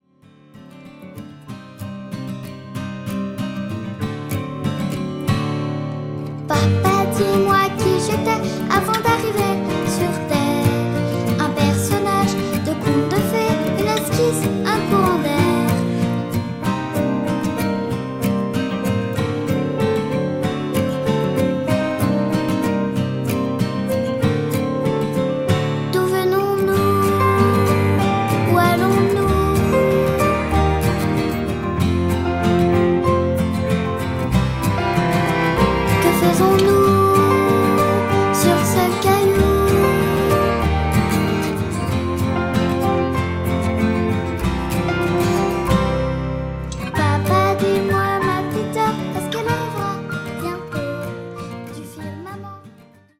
avec voix petite fille